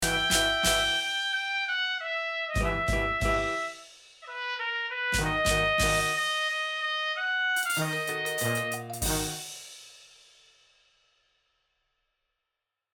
1’36 BPM: 94 Description
Plays end of the track